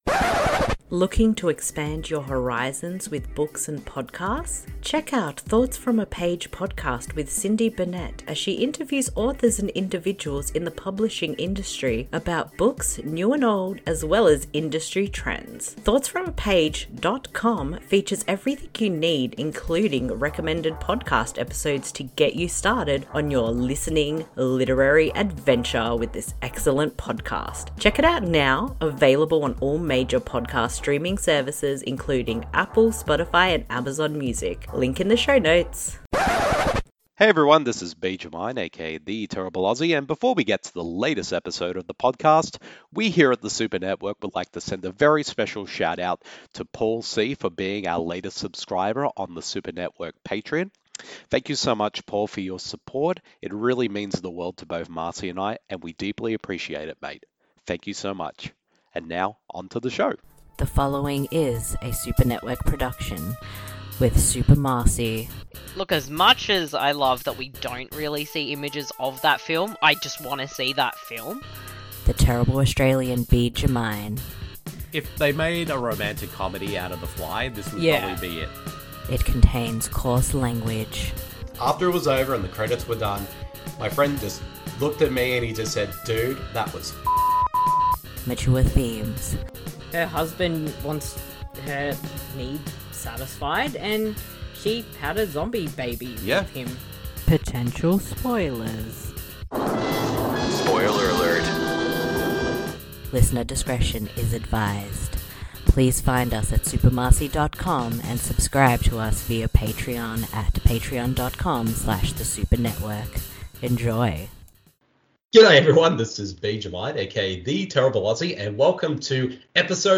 Podcasters Of Horror Episode 14 – Looking at Fear Itself Episodes The Sacrifice and Spooked with guest